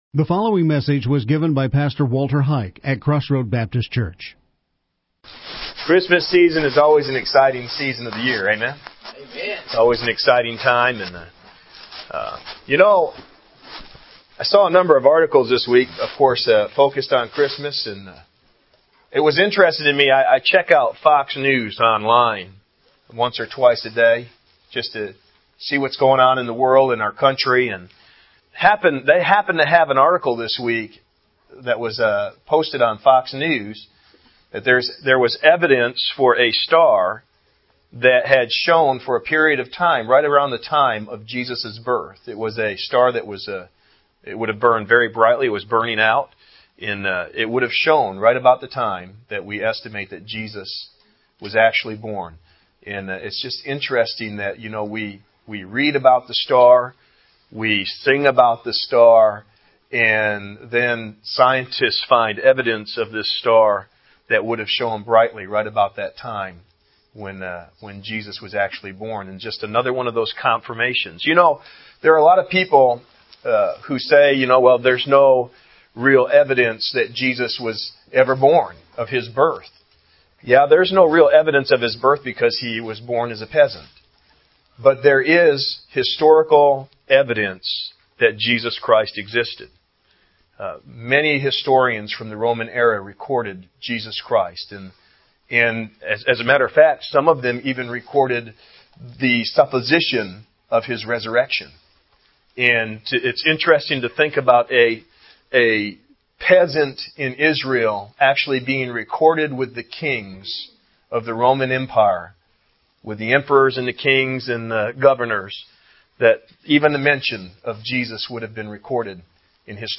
The Scepter, Scholars, and Seekers Outline and Audio Sermon